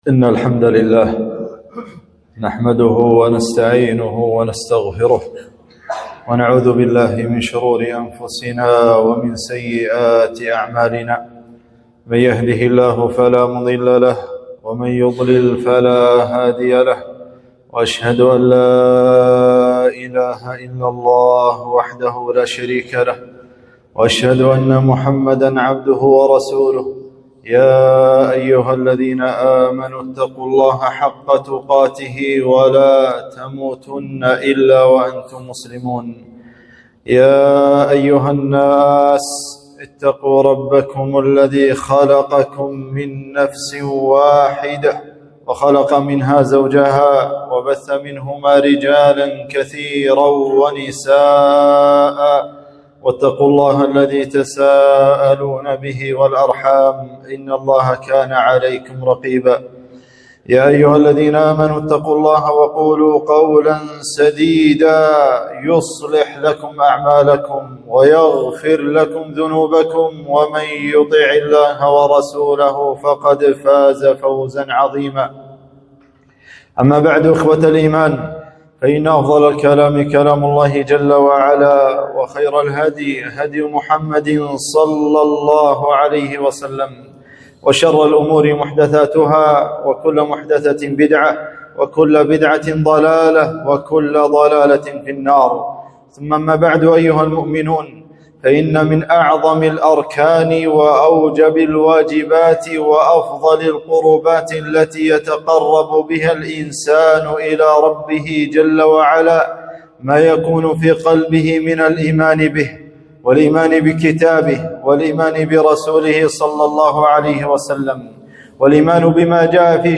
خطبة - الإيمان بالغيب